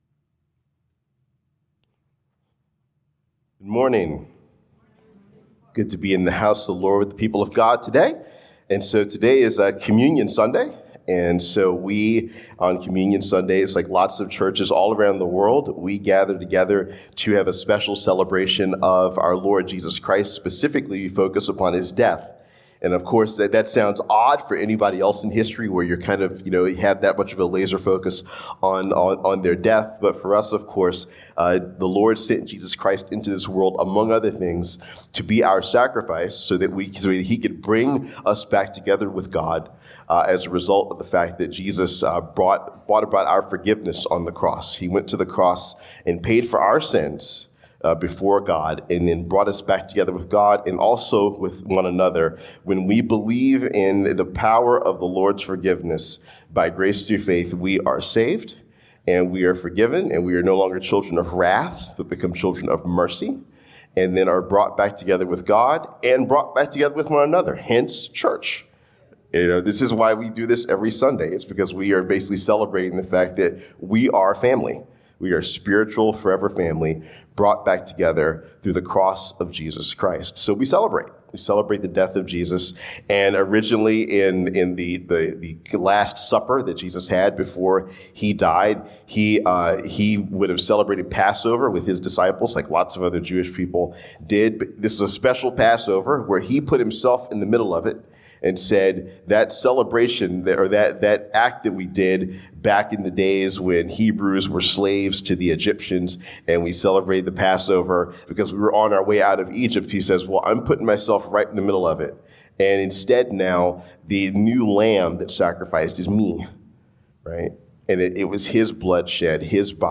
Journey Communion and Prayer Service